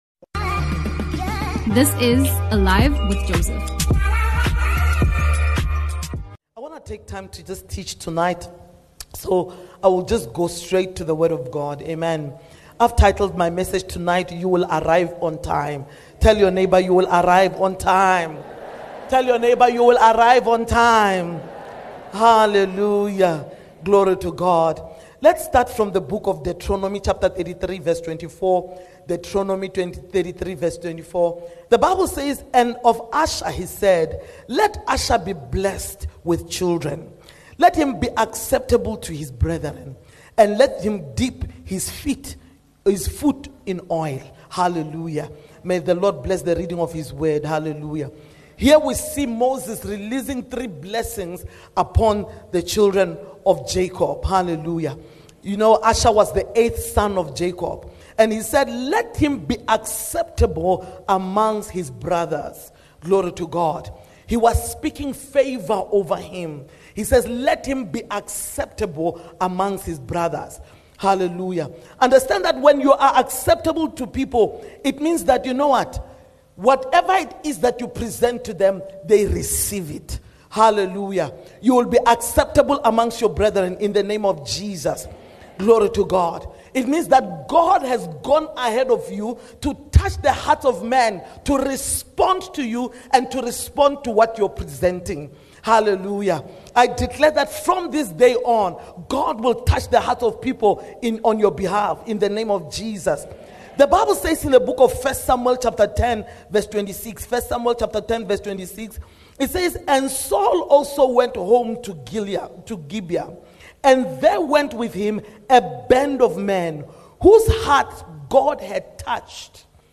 Midweek Service (Blessing and Preservation)